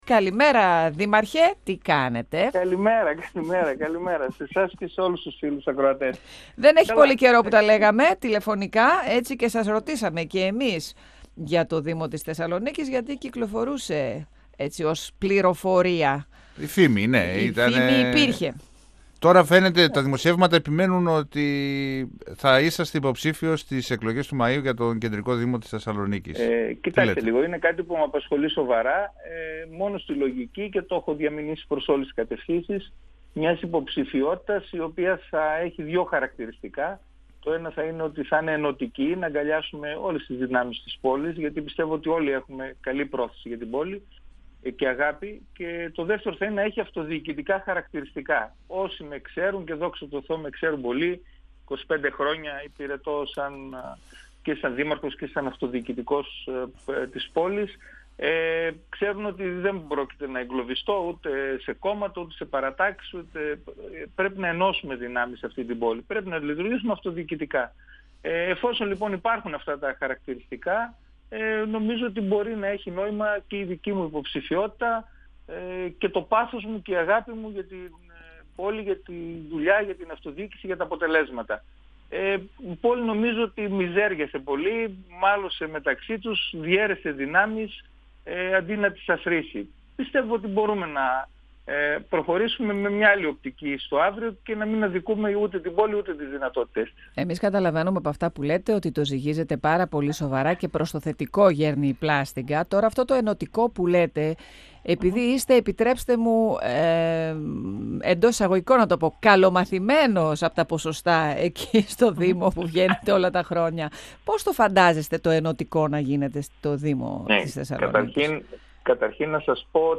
Τις αμέσως επόμενες ημέρες θα αποφασίσει ο δήμαρχος Πυλαίας – Χορτιάτη Ιγνάτιος Καϊτεζίδης, σχετικά με τη διεκδίκηση της δημαρχίας στη Θεσσαλονίκη. Ο κ. Καϊτεζίδης, μιλώντας στον 102FM του Ραδιοφωνικού Σταθμού Μακεδονίας, ανέφερε ότι τον απασχολεί σοβαρά η υποψηφιότητα με συγκεκριμένες όμως προϋποθέσεις, δηλαδή να είναι ενωτική και να έχει αυτοδιοικητικά χαρακτηριστικά.
Συνεντεύξεις